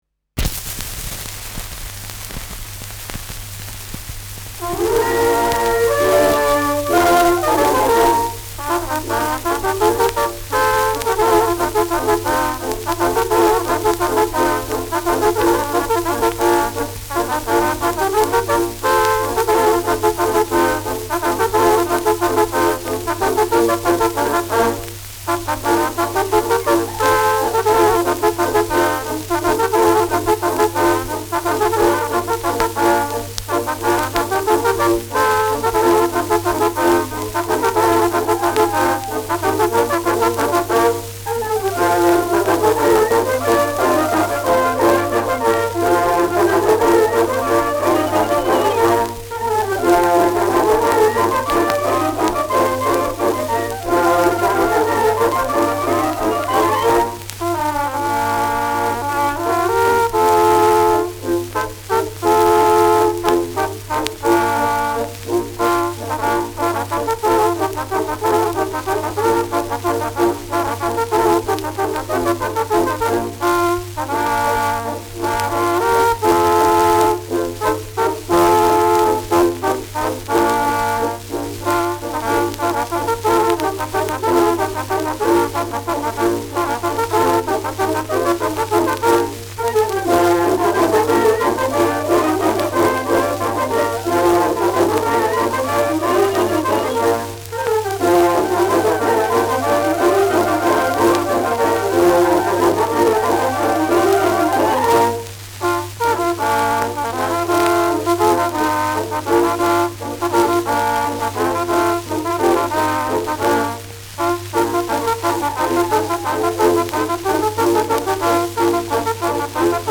Schellackplatte
präsentes Rauschen : präsentes Knistern : leiert : abgespielt : vereinzeltes Knacken
Niederösterreichische Bauernkapelle (Interpretation)
Mit Juchzern.